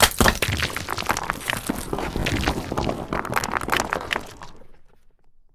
Falling_stones2.ogg